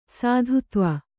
♪ sādhutva